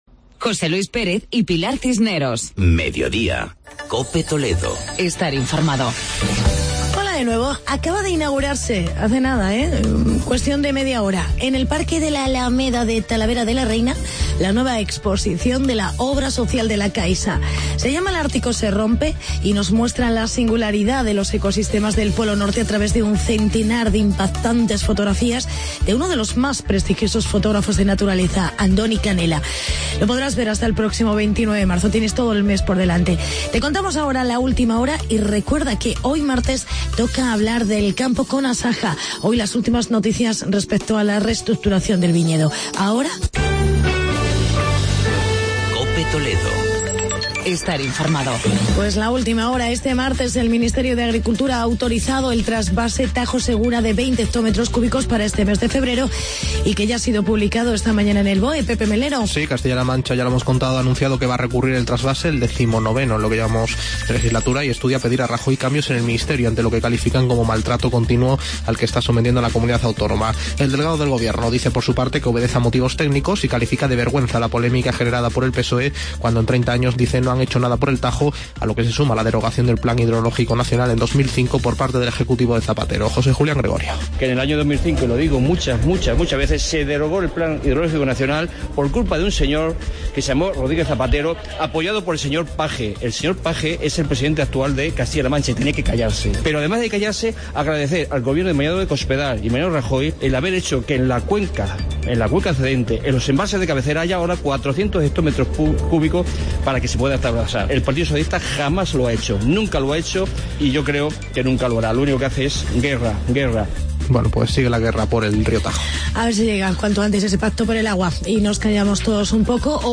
Magazine Mediodia COPE Toledo